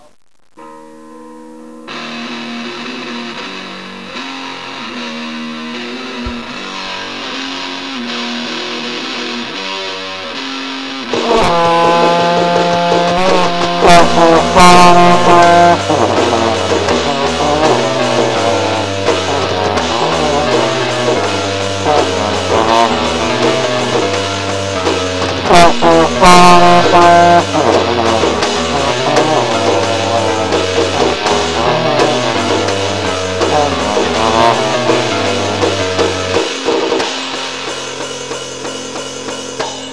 Here's a brief cover